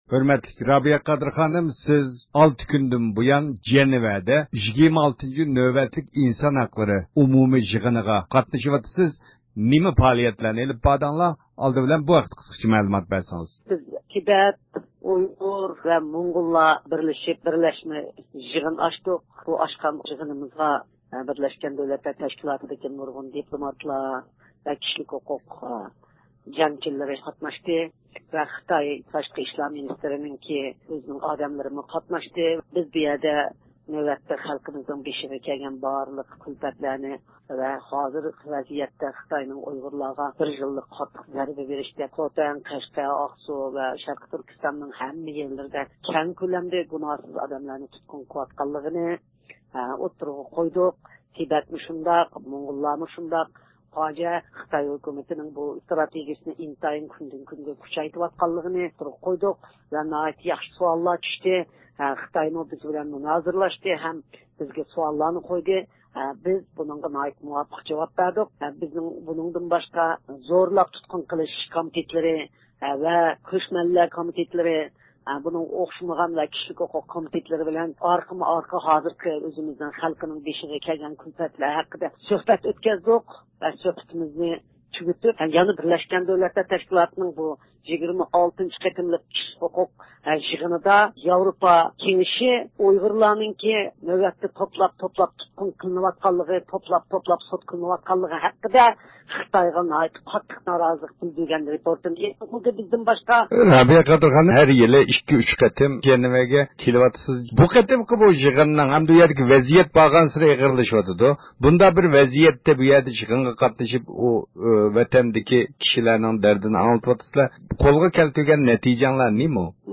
دۇنيا ئۇيغۇر قۇرۇلتىيى رەئىسى رابىيە قادىر خانىم زىيارىتىمىزنى قوبۇل قىلىپ، جەنۋەدە ئېلىپ بارغان 6 كۈنلۈك پائالىيىتىنىڭ غەلىبىلىك ئاخىرلاشقانلىقىنى بىلدۈردى.